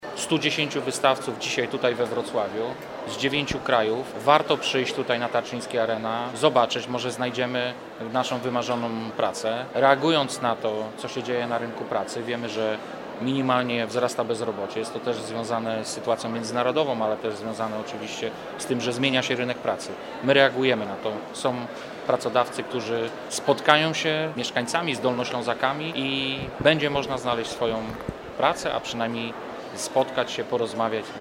Na Tarczyński Arena Wrocław trwają Międzynarodowe Targi Pracy EURES, gdzie swoją ofertę prezentuje 110 wystawców z dziewięciu krajów.
– Sytuacja na rynku pracy się zmienia, jest dynamiczna – mówi Wojciech Bochnak, Wicemarszałek Województwa Dolnośląskiego.